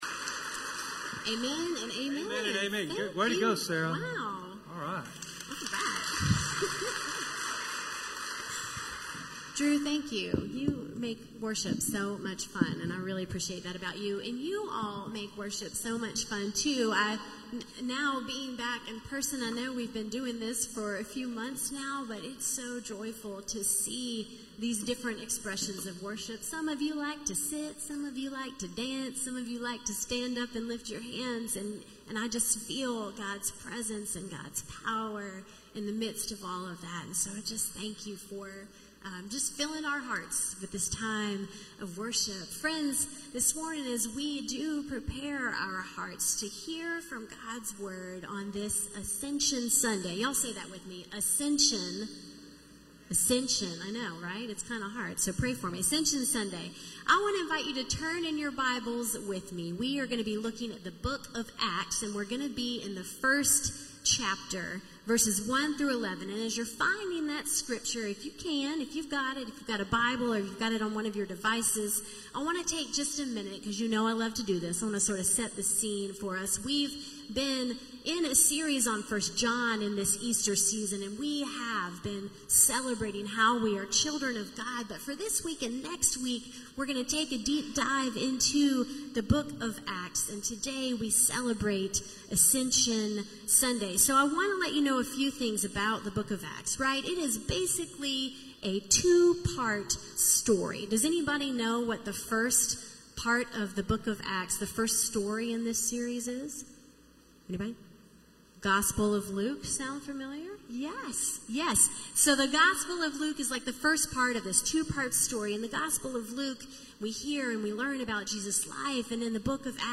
A message from the series "This is How We Know."